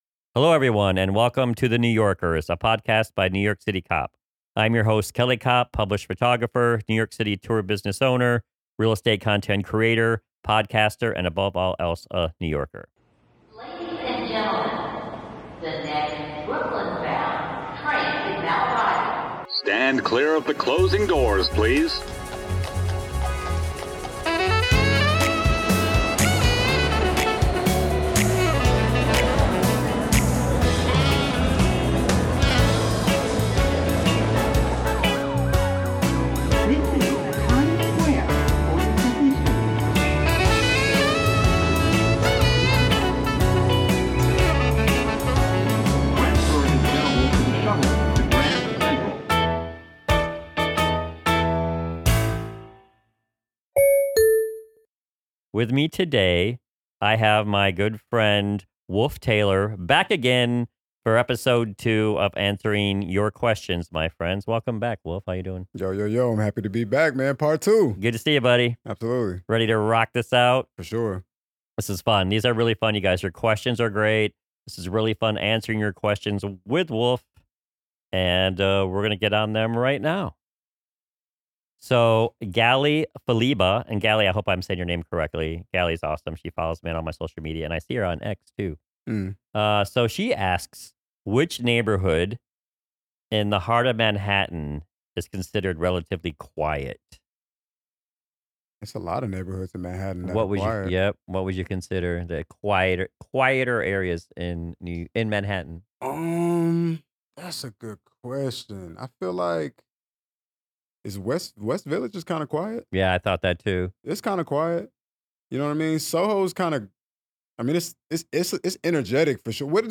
The duo will answer your questions about New York City. Learn what the quietest neighborhoods are of Manhattan.